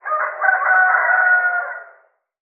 rooster_crowing_03.wav